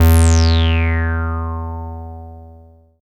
78.01 BASS.wav